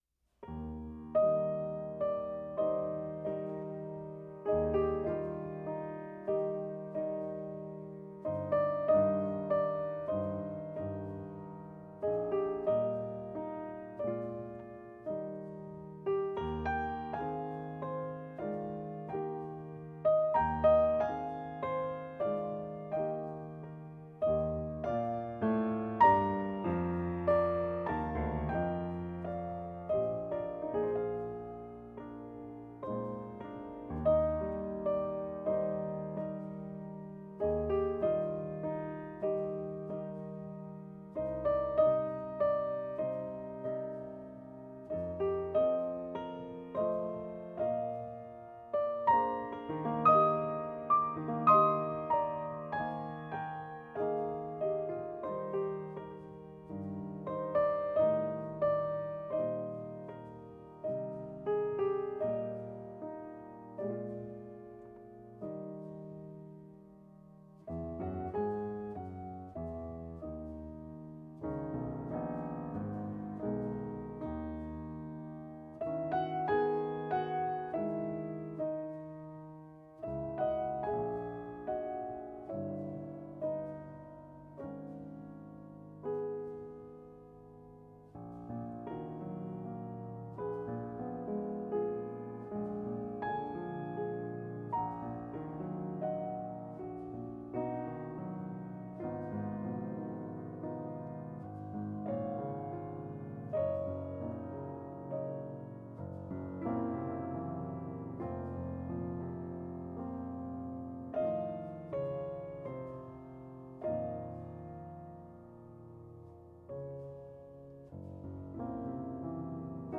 Piano  (View more Intermediate Piano Music)
Classical (View more Classical Piano Music)